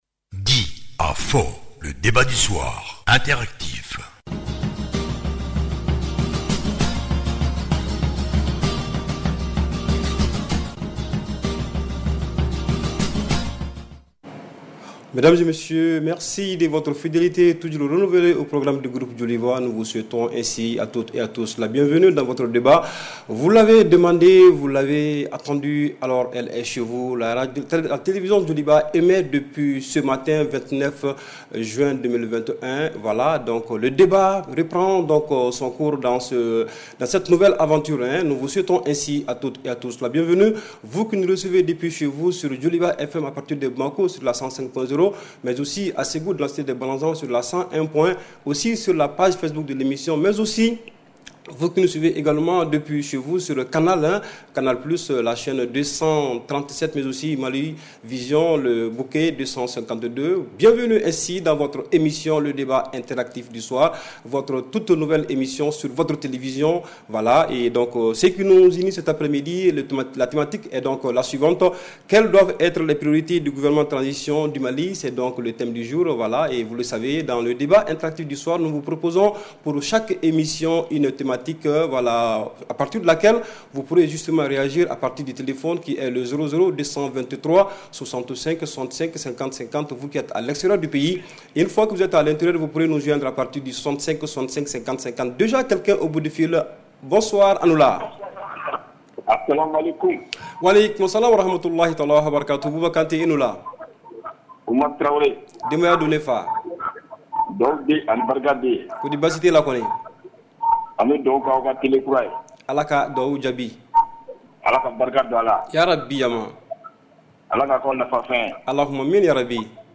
REPLAY 29/06 – « DIS ! » Le Débat Interactif du Soir